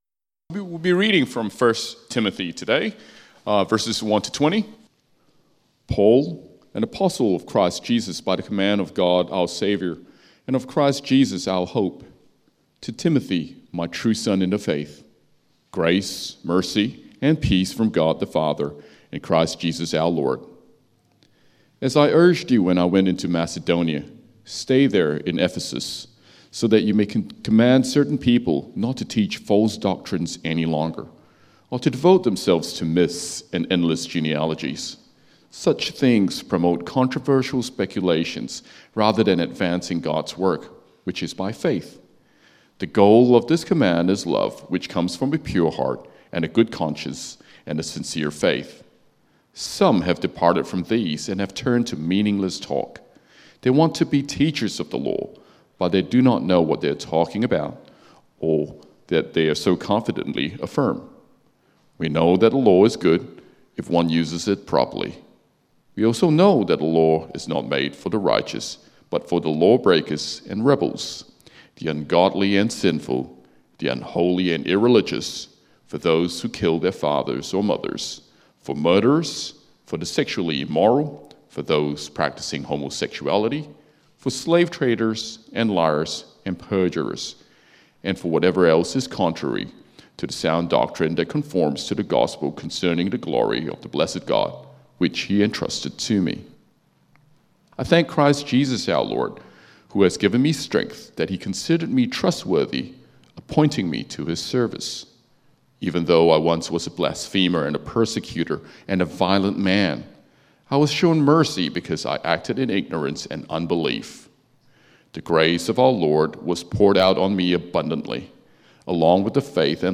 1 Timothy Sermon outline